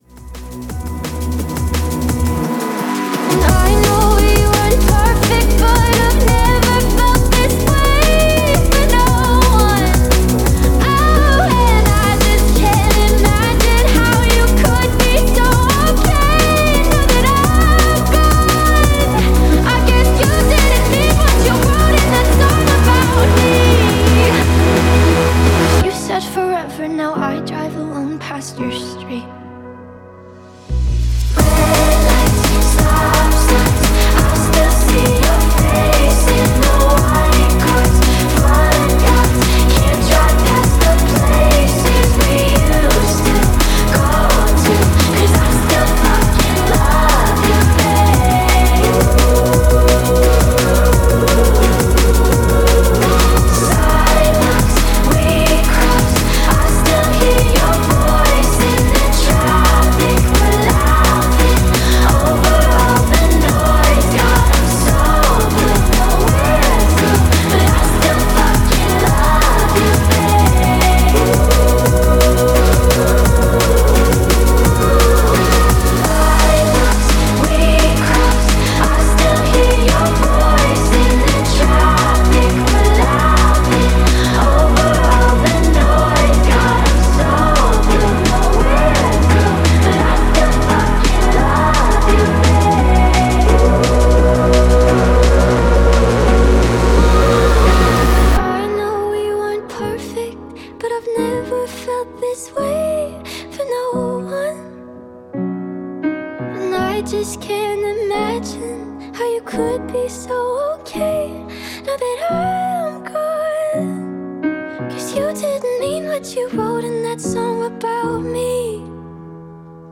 drum and bass
electronic music